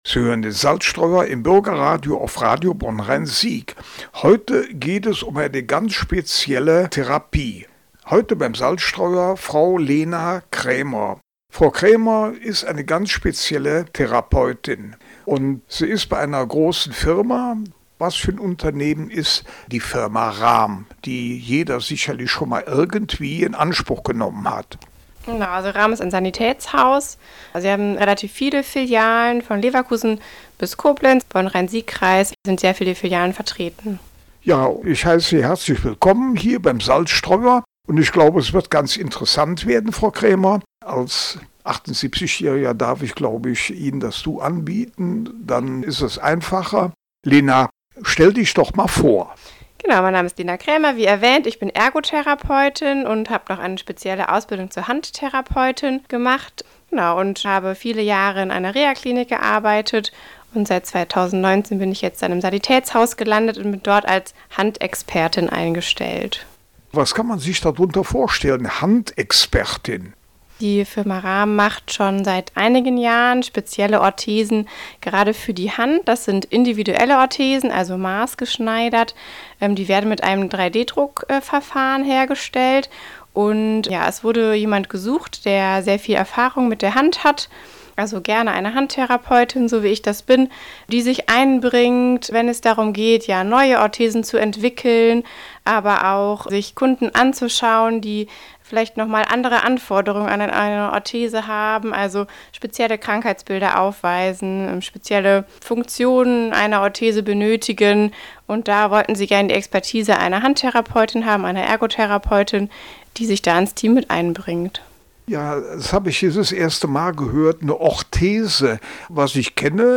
rahm Kollegin im Radio-Interview